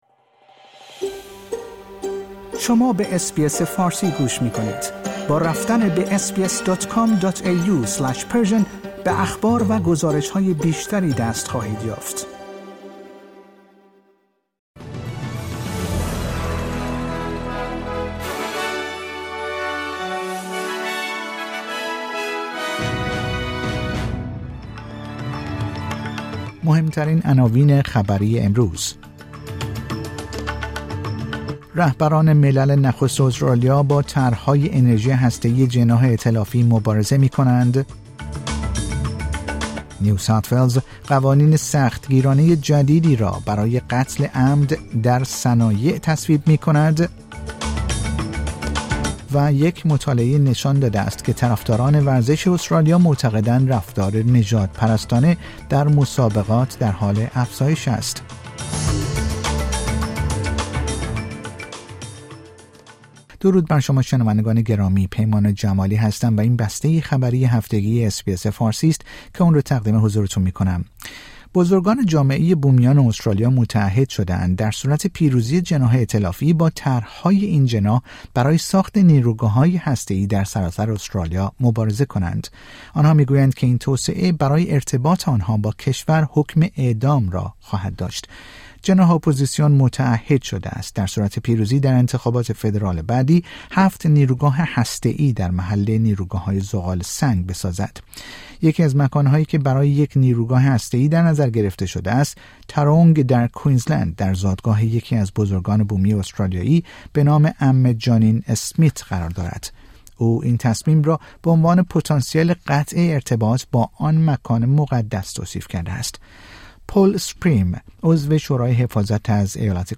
در این پادکست خبری مهمترین اخبار استرالیا، جهان و ایران در یک هفته منتهی به شنبه ۲۱ جون ۲۰۲۴ ارائه شده است.